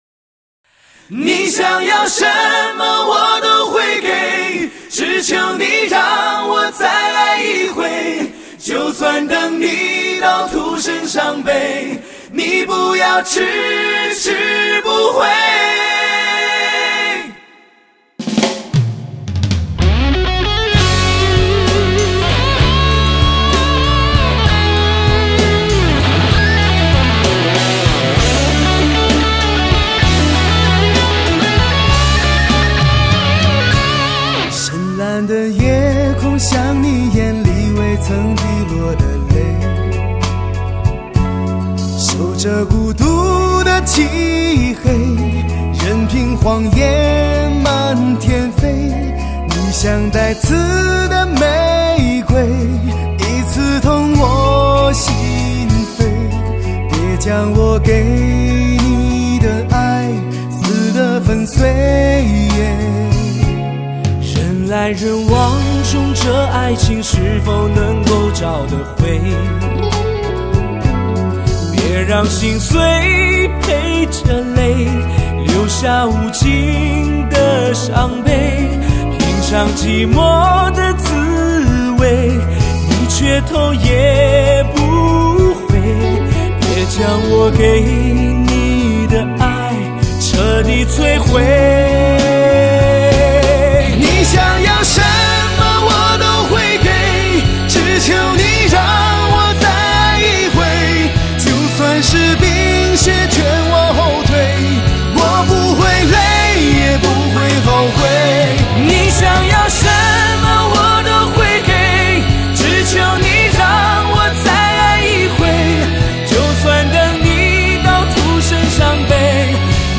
一种心痛，一种乞求
豪放，实话实说。